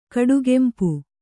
♪ kaḍugempu